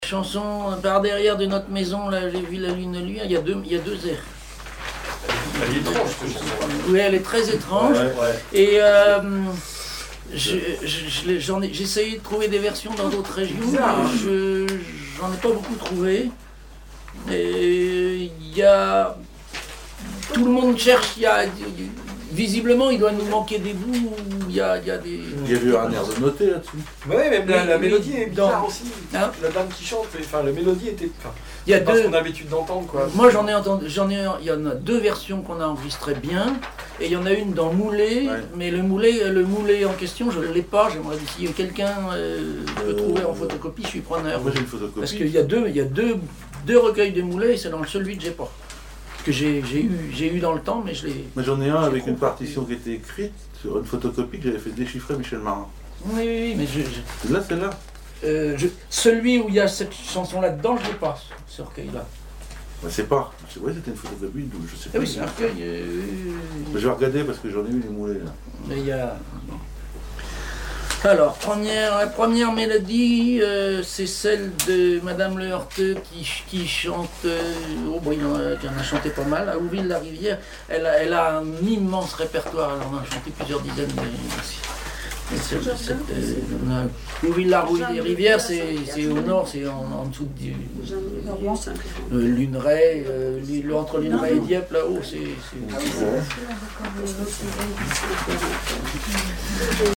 Commentaire sur la chanson Par derrière notre maison
Catégorie Témoignage